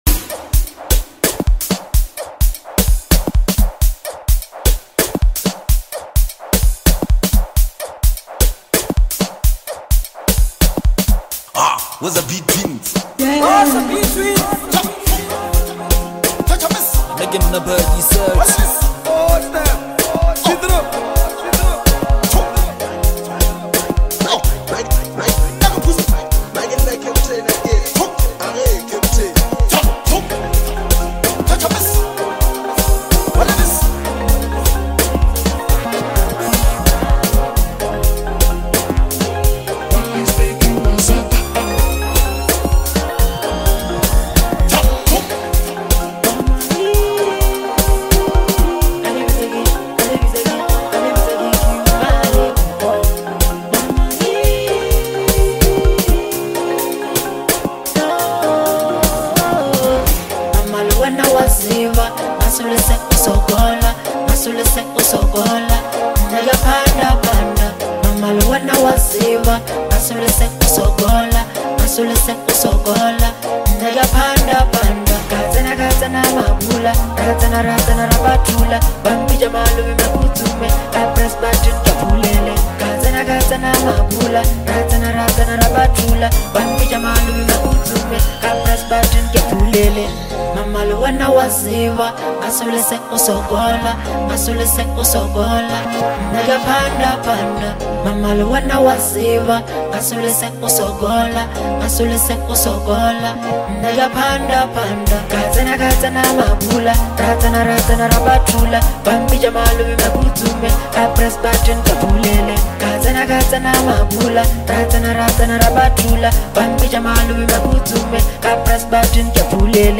hard hitting street anthem